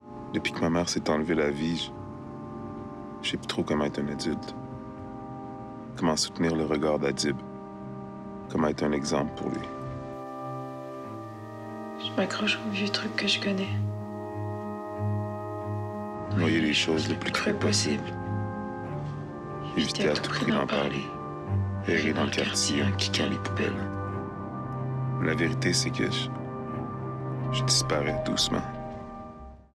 Démo de voix
Narration - Dominos